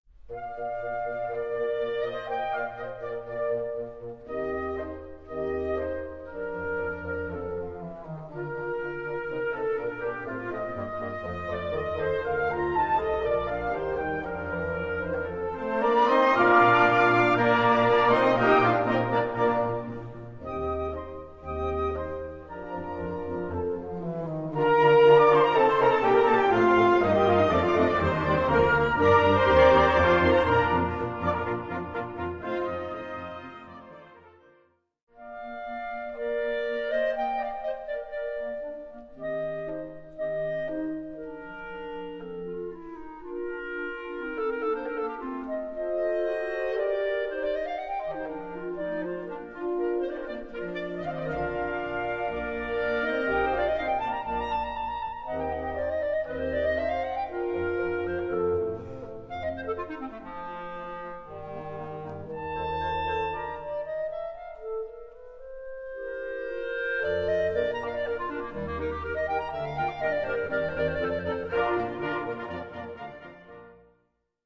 Gattung: Klarinette & Klavier (mittelschwer - schwer)